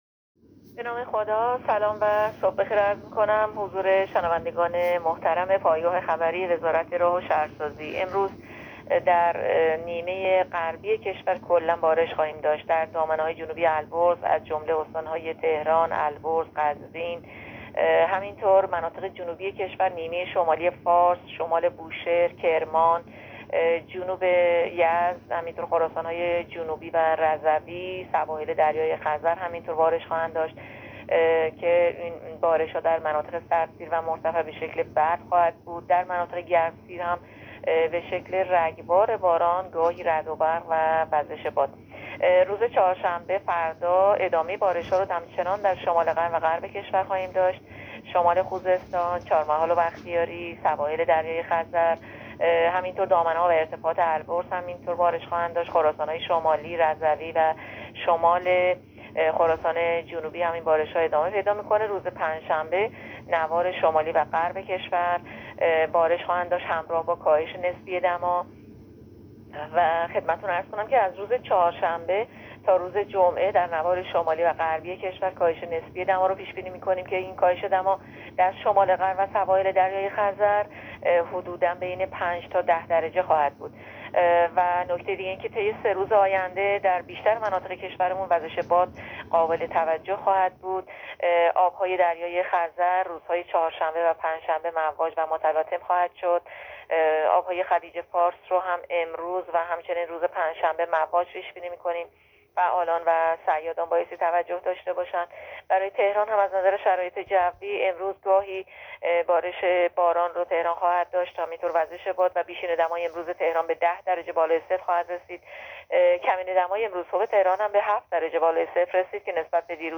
گزارش رادیو اینترنتی پایگاه‌خبری از آخرین وضعیت آب‌وهوای ۱۸ بهمن؛